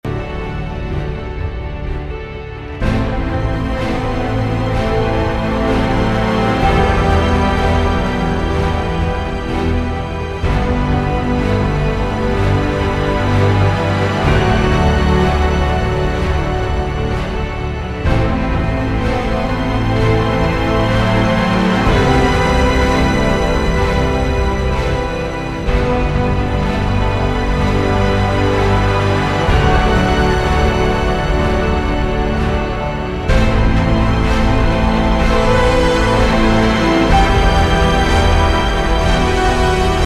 саундтреки
без слов
инструментальные
оркестр
Нежная, спокойная мелодия